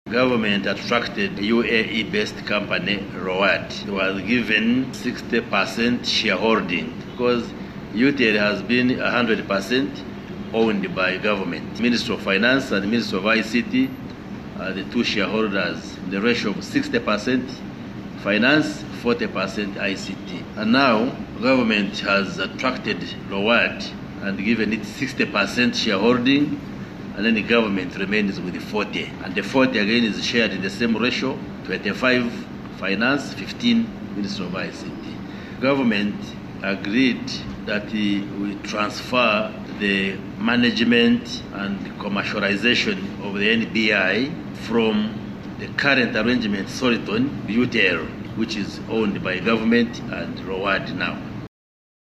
AUDIO: Minister Chris Baryomunsi
The Minister made the revelation while leading entities under the Ministry to present the Ministerial Policy Statement to the Parliament Committee on ICT and National Guidance on Thursday, 03 April 2025.
Hon. Chris Baryomunsi on new Rowad Deal.mp3